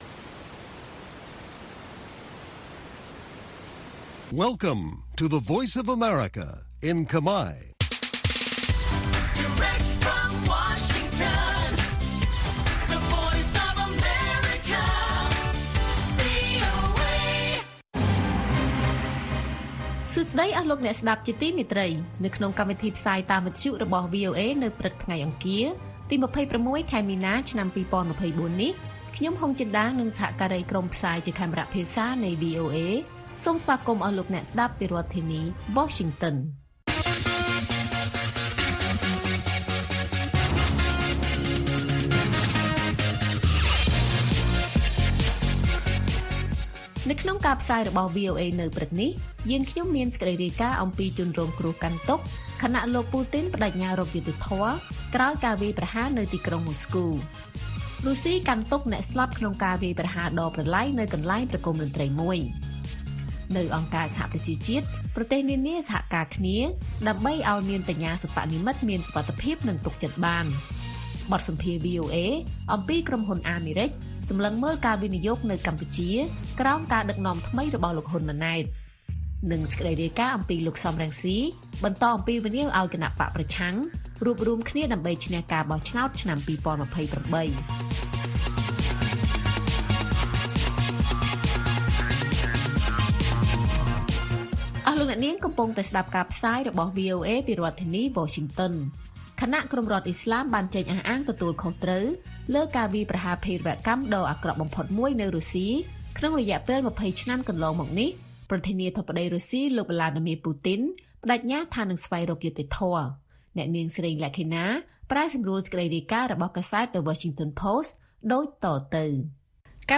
ព័ត៌មានពេលព្រឹក ២៦ មីនា៖ បទសម្ភាសន៍អំពីក្រុមហ៊ុនអាមេរិកសម្លឹងមើលការវិនិយោគនៅកម្ពុជា ក្រោមការដឹកនាំថ្មីរបស់លោក ហ៊ុន ម៉ាណែត